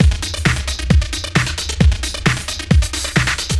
hooj_102_slow_loop.wav